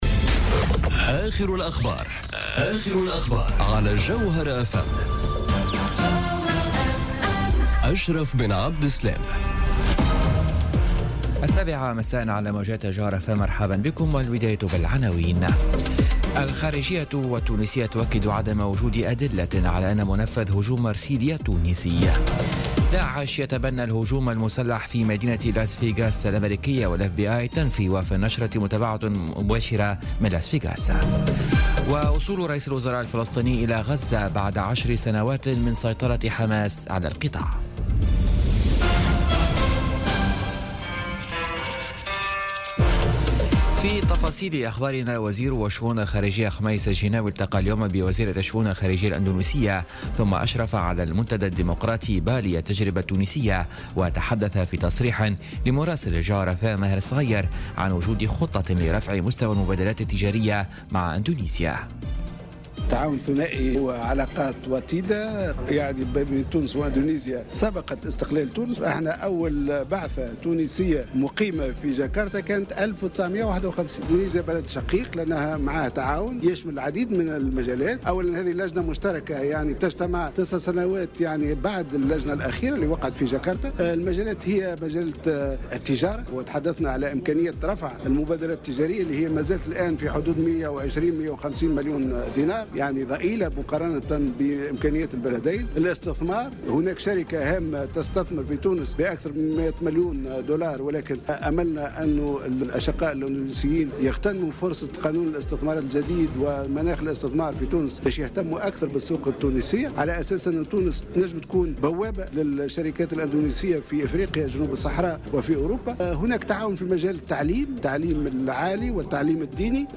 نشرة أخبار السابعة مساء ليوم الاثنين 2 أكتوبر 2017